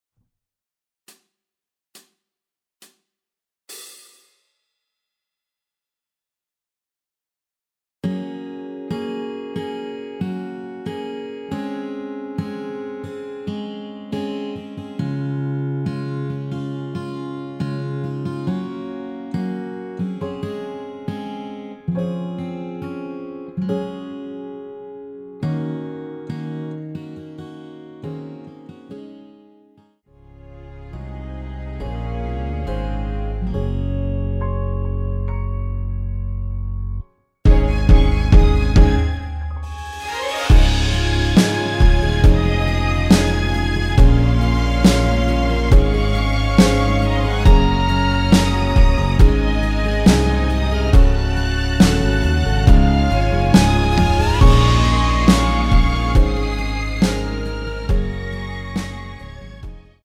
전주 없이 시작하는 곡이라서 시작 카운트 만들어놓았습니다.(미리듣기 확인)
원키에서(+5)올린 (1절앞+후렴)으로 진행되는 MR입니다.
Db
앞부분30초, 뒷부분30초씩 편집해서 올려 드리고 있습니다.
중간에 음이 끈어지고 다시 나오는 이유는